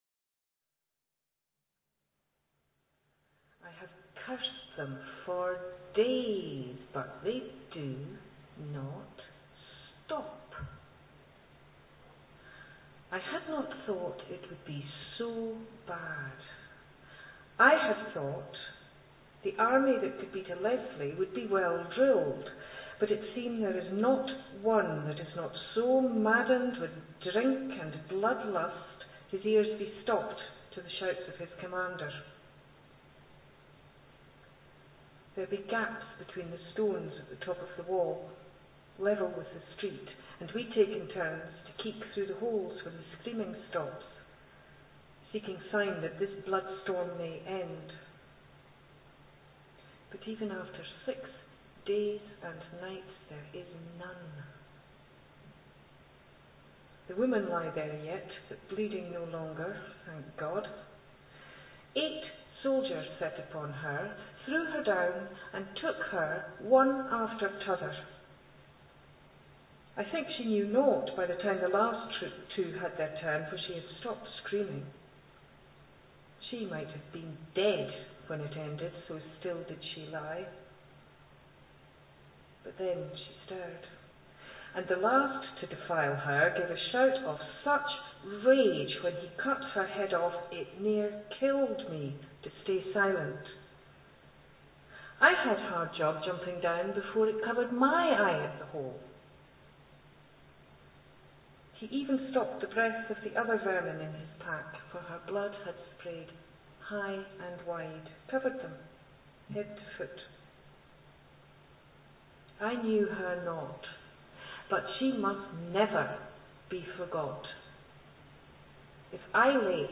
Second reading extract, at the Tolbooth Theatre Stirling
THESE READINGS ARE EXTRACTS FROM A RECORDING MADE BY
THE TOLBOOTH THEATRE IN STIRLING